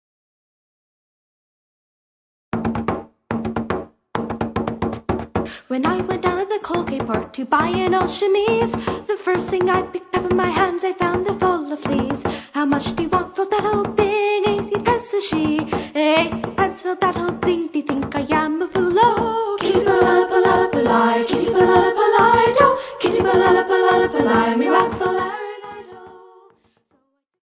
who played Celtic, French-Canadian and original music